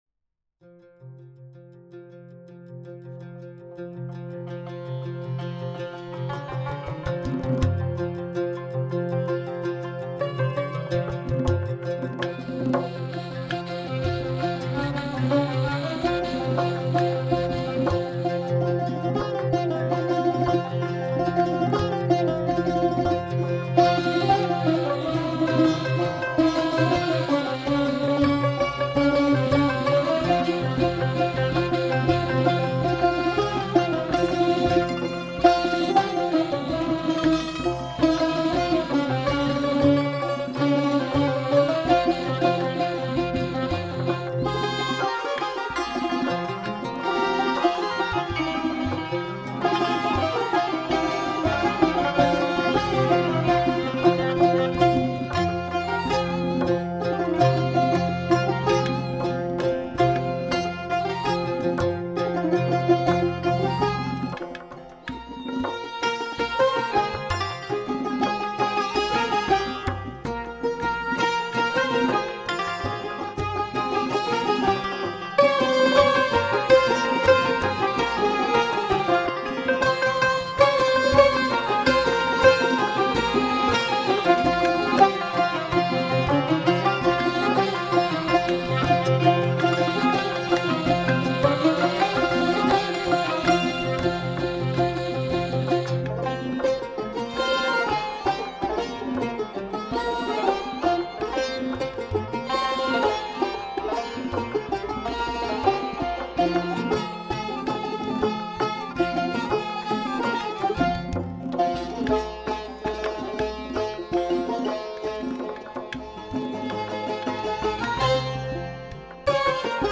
تار
نی
سنتور
کمانچه
عود
تنبک